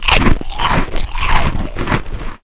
PZ_Eating.wav